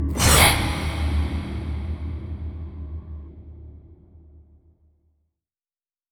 04_书店内_鬼差哈气.wav